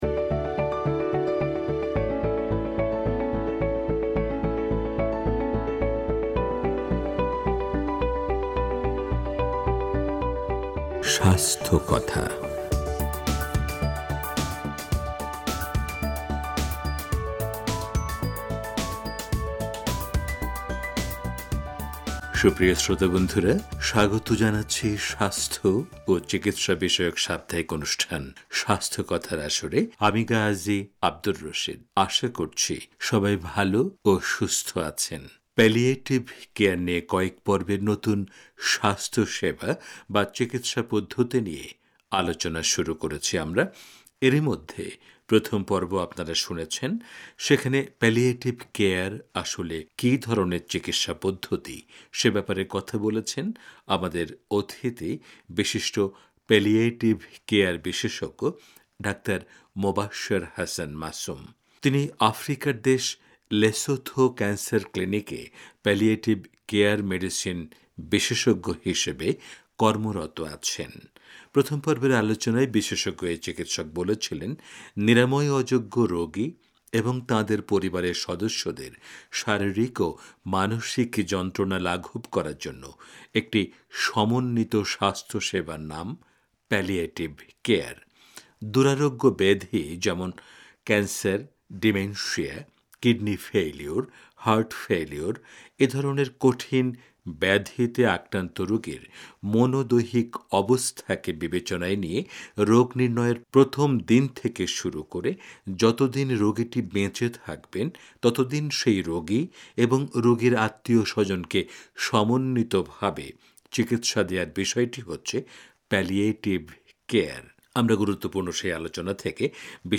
তো চলুন আজ আমরা এ বিষয়ক সাক্ষাৎকারের দ্বিতীয় পর্বে যাই।